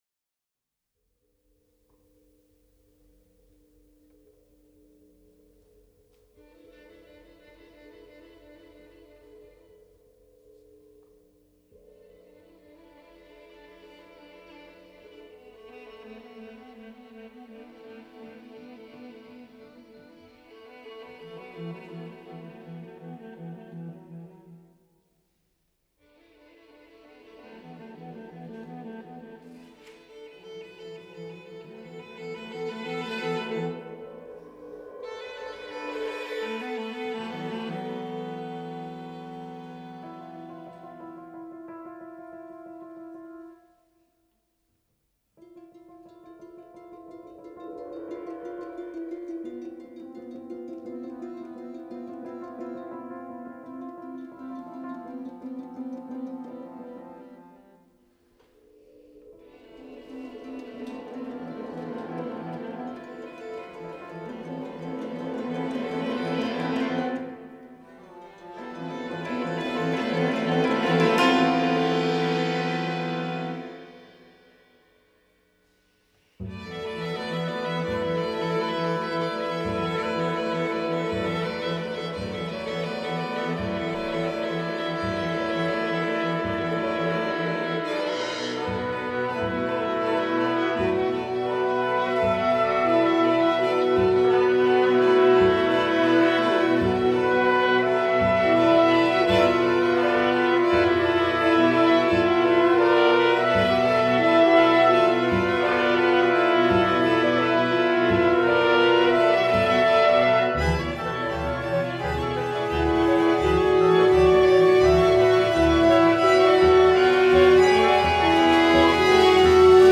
pro komorní ansámbl / for chamber ensemble